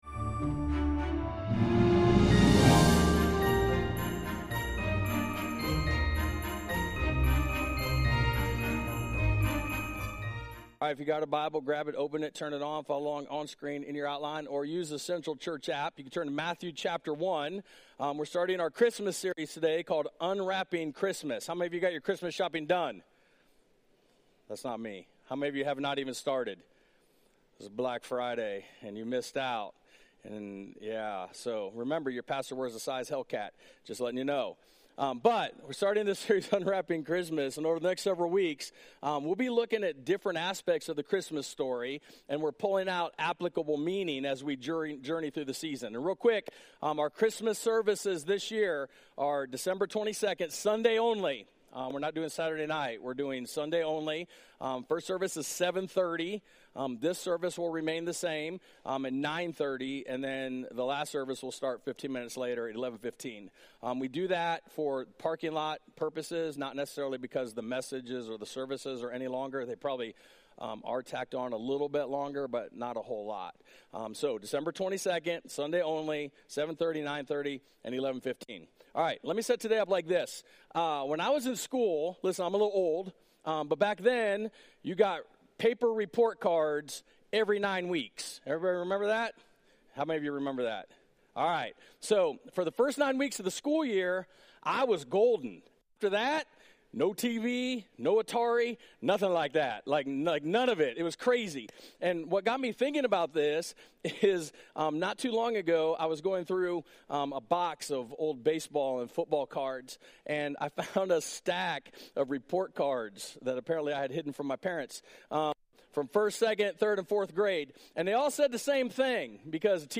We kicked off the Christmas season with our new series, "Unwrapping Christmas" and the first message, "Unwrapping Restoration" - inspired by Matthew 1-2.